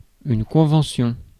Ääntäminen
IPA: [kɔ̃.vɑ̃.sjɔ̃]